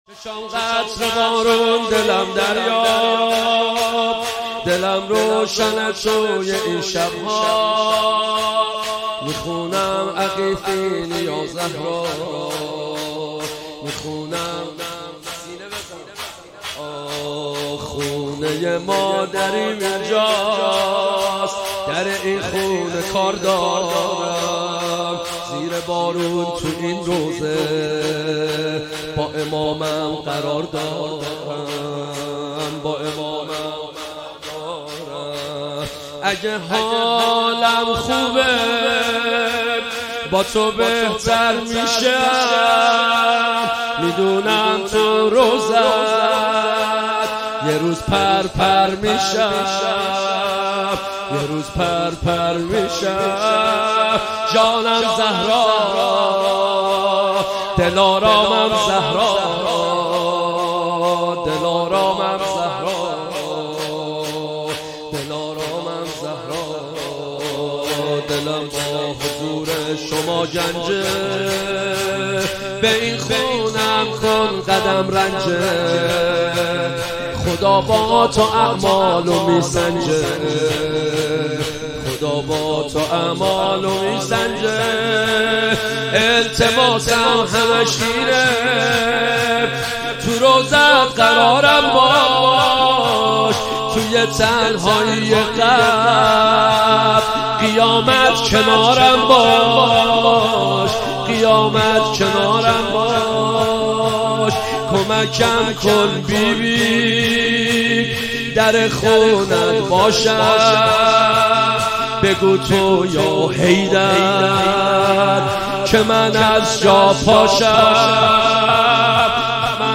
ایام فاطمیه (س) 1440